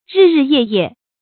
日日夜夜 注音： ㄖㄧˋ ㄖㄧˋ ㄧㄜˋ ㄧㄜˋ 讀音讀法： 意思解釋： 每天每夜。形容延續的時間長。